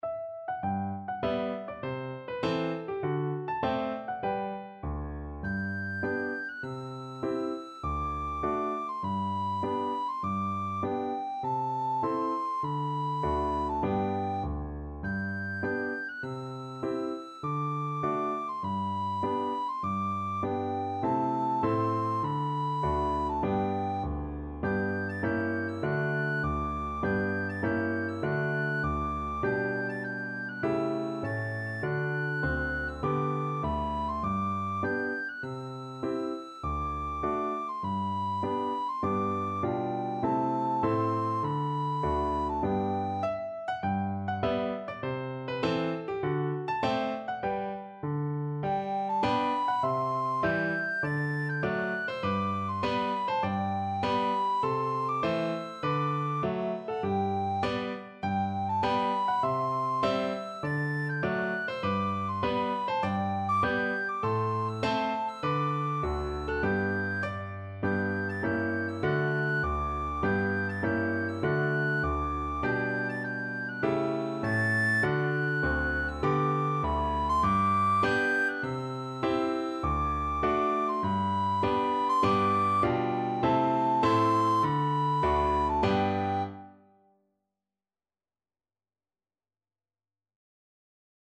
Free Sheet music for Soprano (Descant) Recorder
G major (Sounding Pitch) (View more G major Music for Recorder )
4/4 (View more 4/4 Music)
Traditional (View more Traditional Recorder Music)